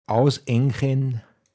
pinzgauer mundart
ausenke(l)n Fuß verrenken